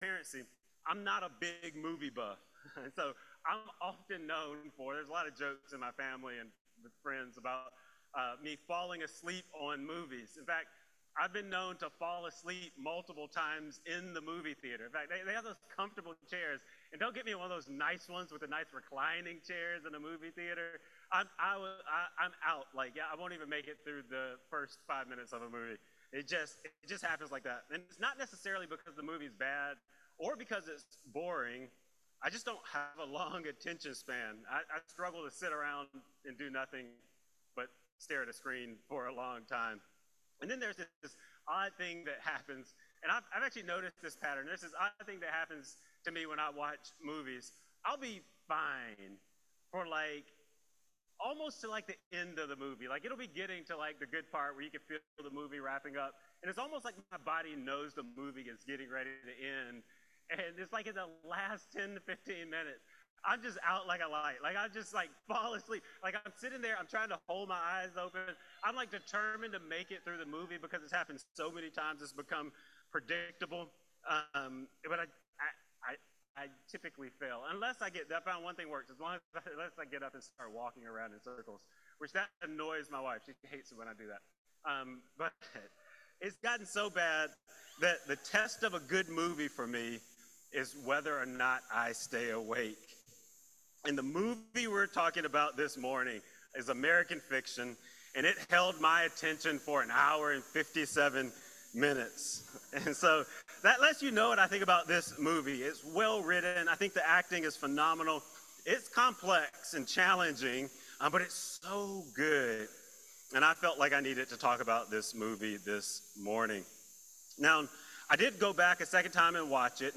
A message from the series "God in the movies 2024."